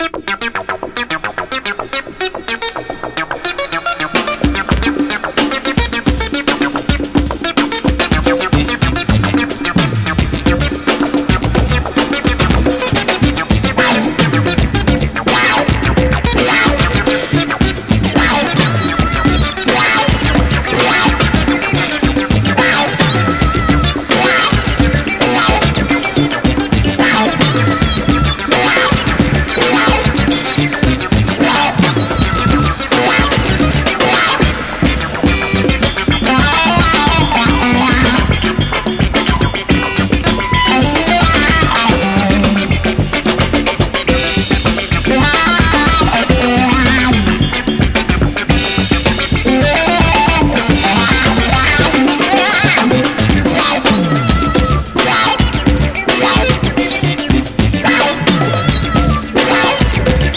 JAZZ / JAPANESE / JAZZ VOCAL (JPN)